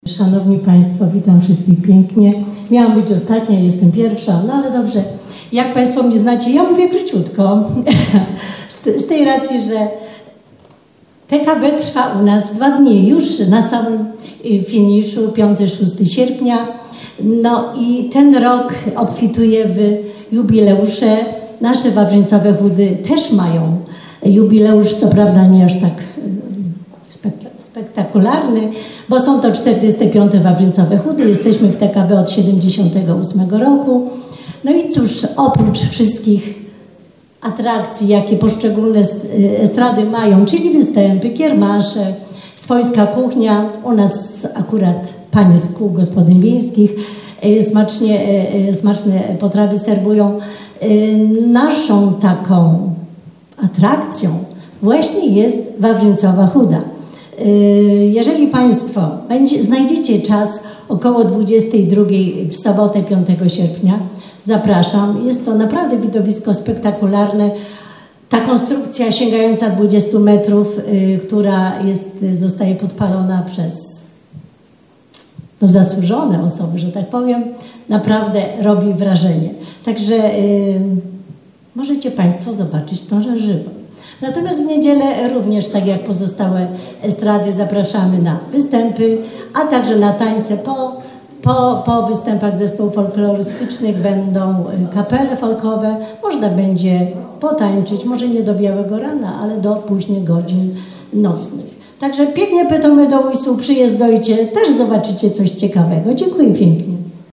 Reprezentanci Wisły, Szczyrku, Żywca, Oświęcimia, Makowa Podhalańskiego, Istebnej, Ujsół i Jabłonkowa na Zaolziu wystąpili dzisiaj podczas konferencji prasowej w Miejskiej Bibliotece Publicznej w Wiśle.